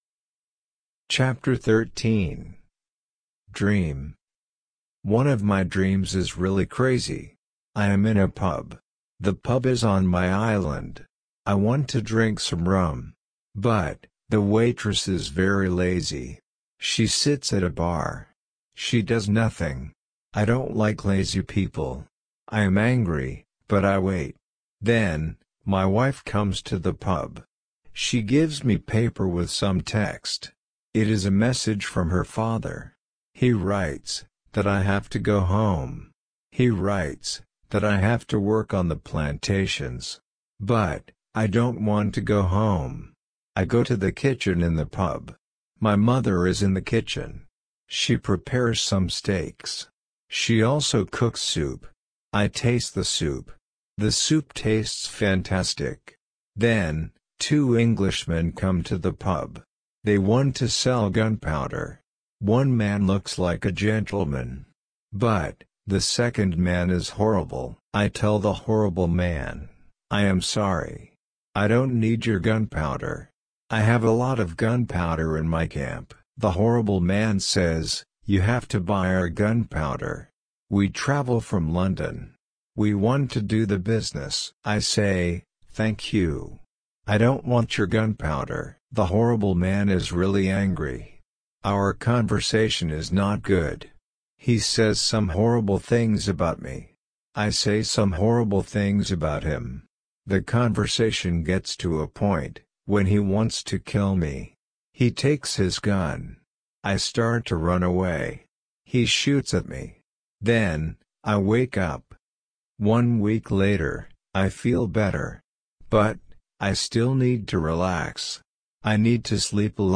RC-L1-Ch13-slow.mp3